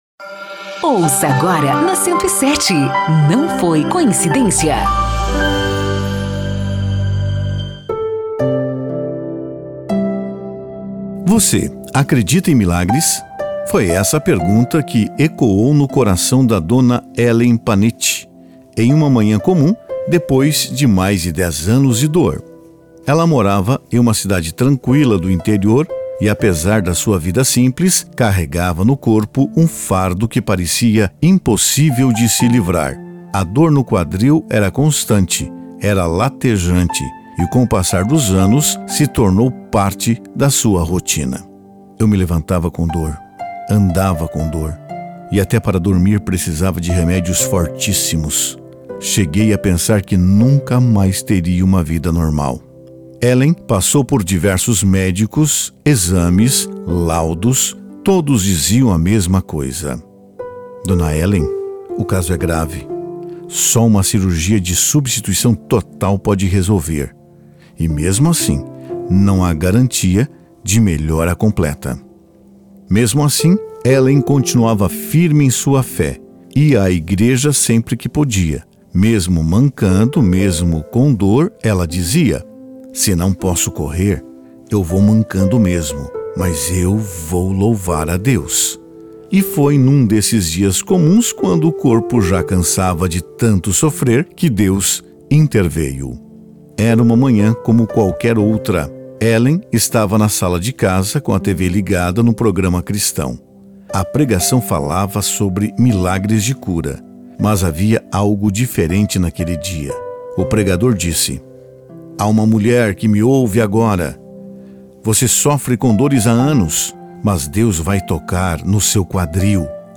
TESTEMUNHOS